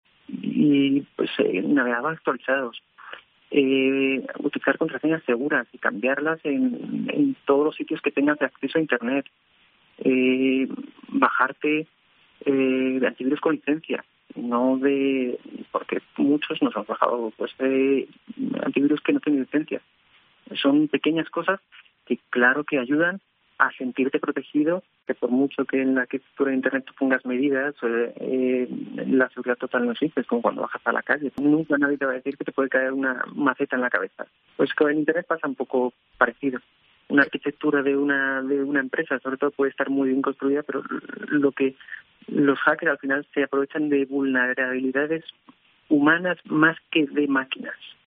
Un equipo de COPE Andalucía habla con Grupo de Apoyo técnico contra la ciberdelincuencia de la Policía Nacional